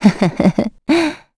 Gremory-Vox_Happy2.wav